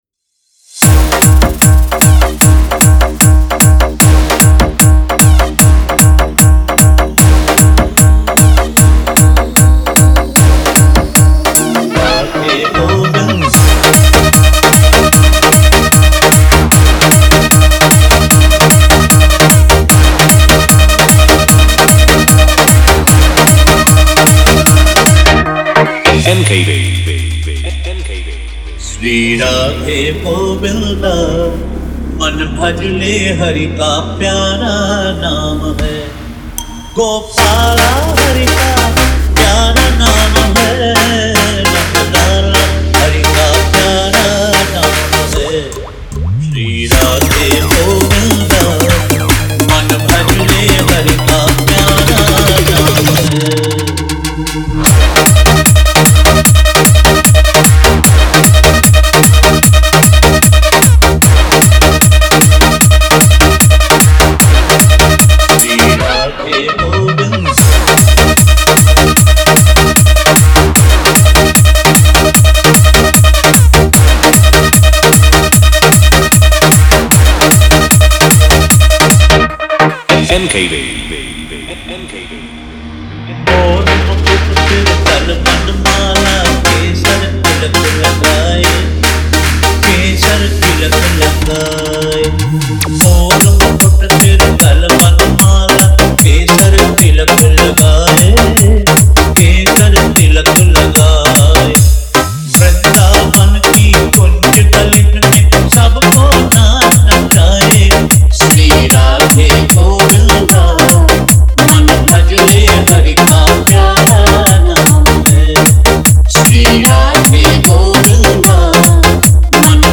Devotional Bass Mix